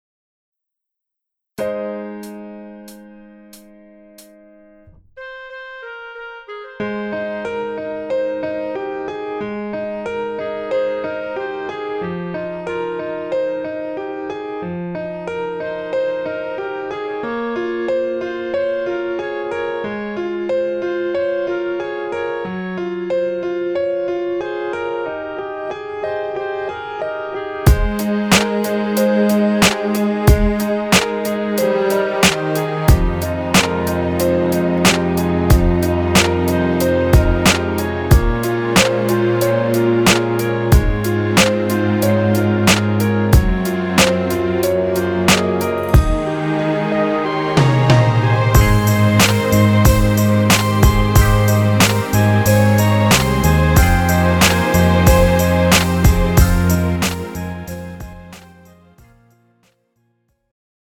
장르 가요 구분